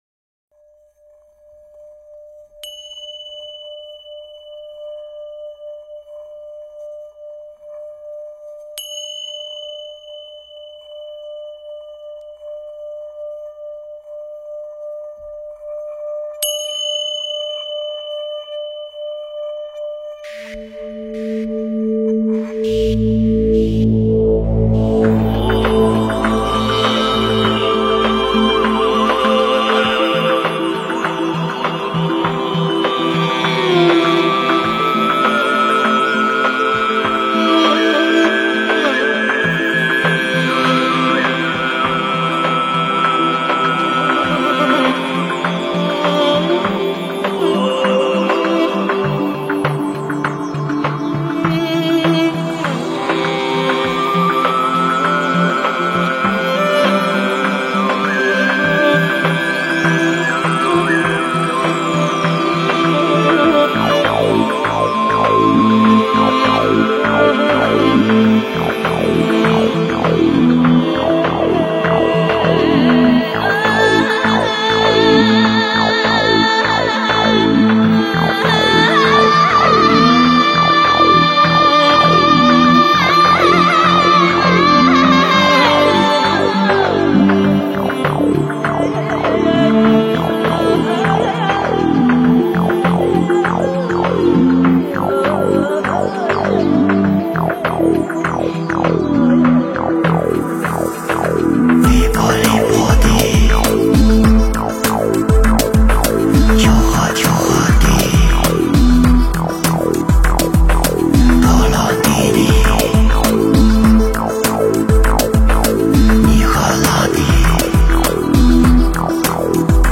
诵经
佛音 诵经 佛教音乐 返回列表 上一篇： 莲师心咒 下一篇： 圣无量寿决定光明王陀罗尼 相关文章 牟尼赞--闽南佛学院 牟尼赞--闽南佛学院...